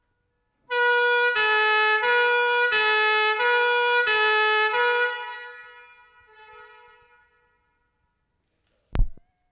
London, England June 4/75
A 1-6. LONDON EMERGENCY WARNING SIGNALS
Fiamm ambulance horn (M2).